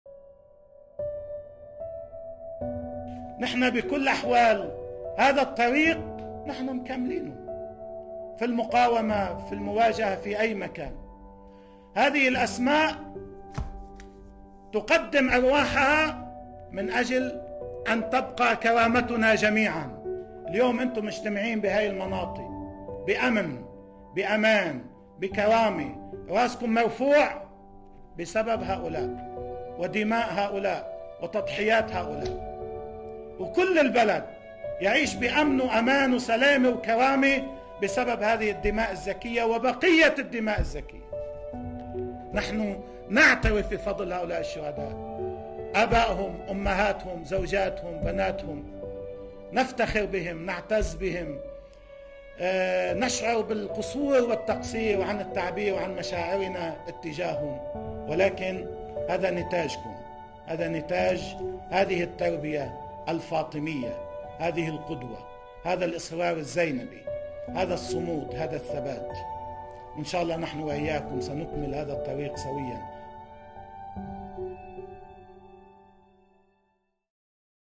في مقطع صوتي وفيدئو.. الشهيد السيد حسن نصرالله يتحدث عن طريق المقاومة والمواجهة، ويؤكد اننا باقون على هذا الصمود والثبات ونكمل الطريق سويا.